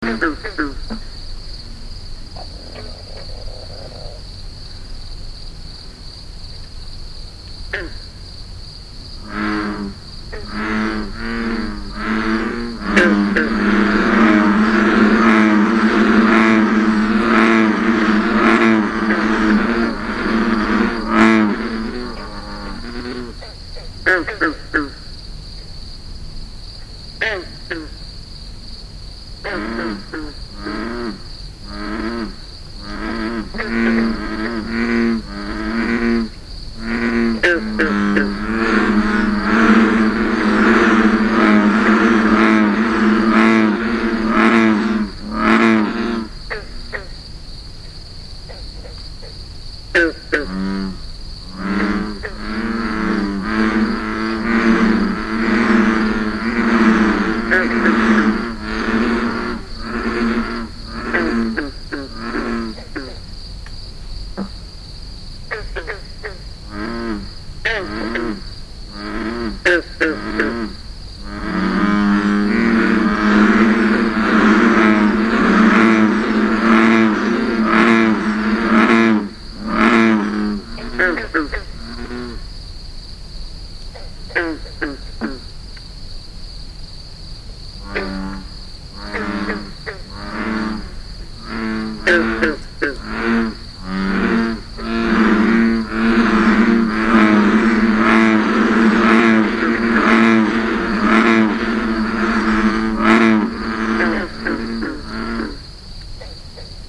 After the toads, the pond begins to swell with a chorus of bullfrogs.
The green frogs give a distinct, high-pitched warning to their fellows about the potential danger we bring.
Audio Player: Green, Leopard & Bullfrogs
frogs-at-hills-pond-5-29-15.mp3